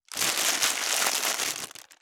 611スーパーの袋,袋,買い出しの音,ゴミ出しの音,袋を運ぶ音,
効果音